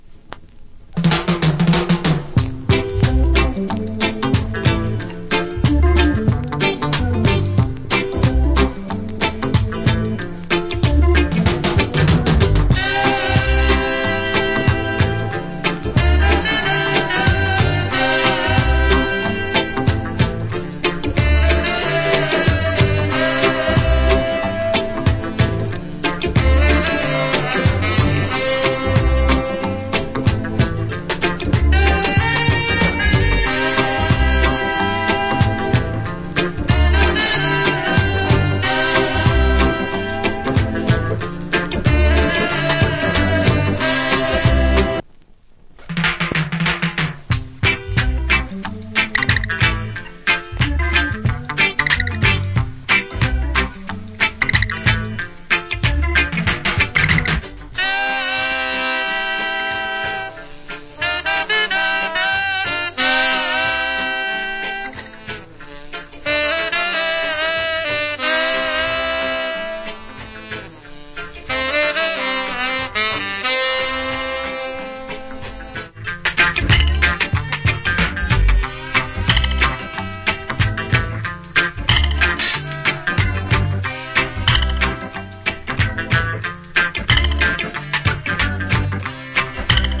Mellow Reggae Inst.